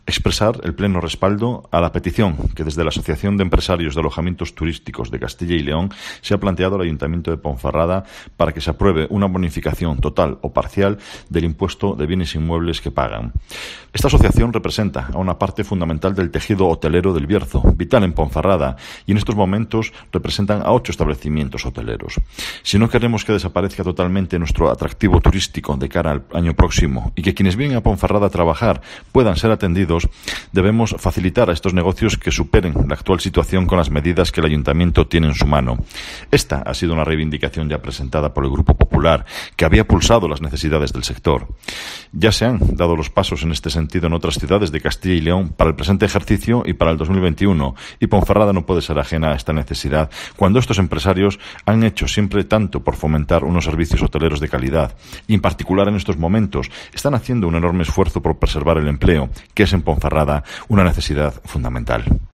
AUDIO: Escucha aquí las palabras del portavoz del PP en Ponferrada, Marco Morala